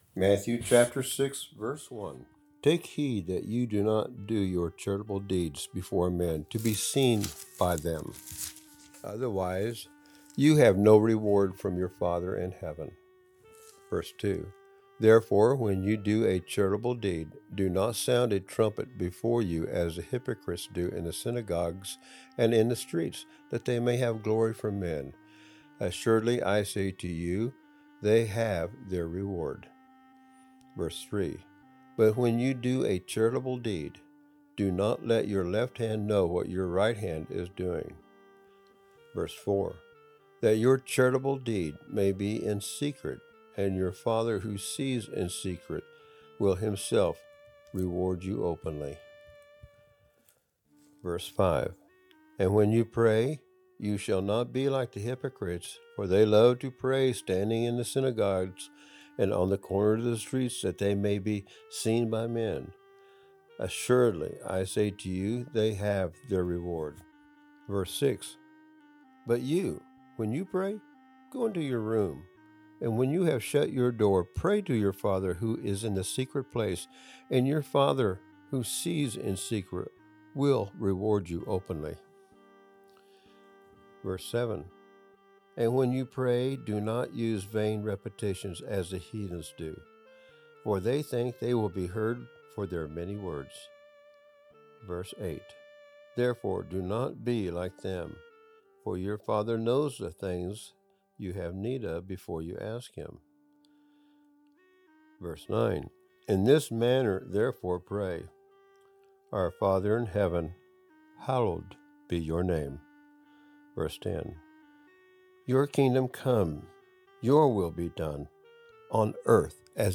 More posts from More from Unfolding the Bible More posts in Unfolding the Bible » Audio Reading Matthew Chapter 6 Unfolding the Bible: Matthew Chapter 5 Unfolding the Bible: Matthew 4 Unfolding the Bible: Matthew 3